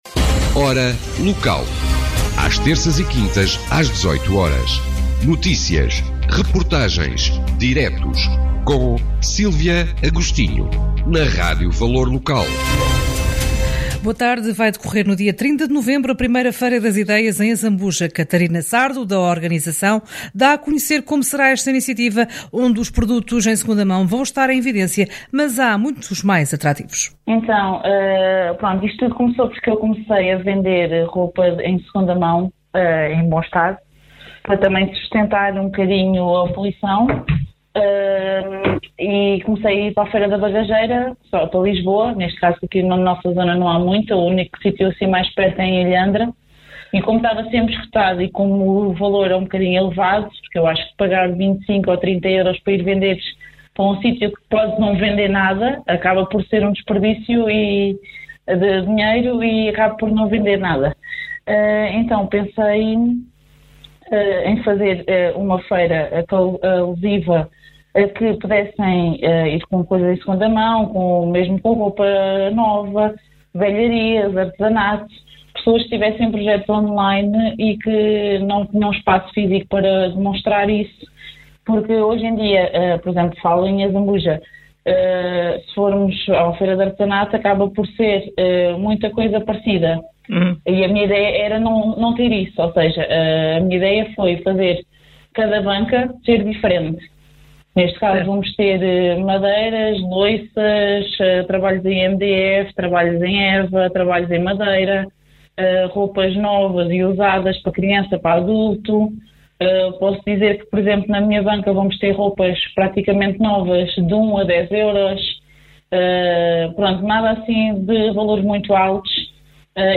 O Valor Local esteve presente na inauguração e falou com produtores e com o presidente da Câmara sobre o certame e o setor.